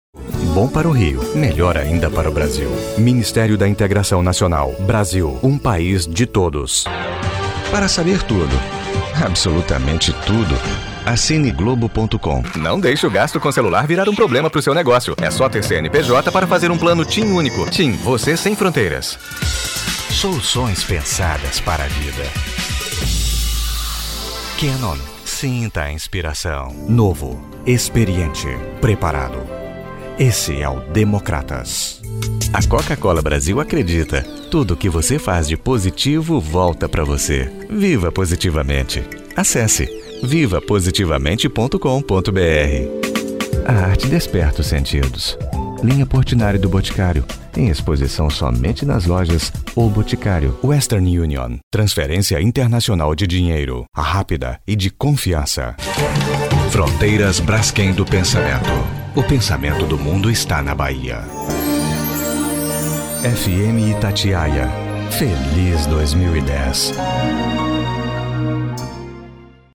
brasilianisch
Sprechprobe: eLearning (Muttersprache):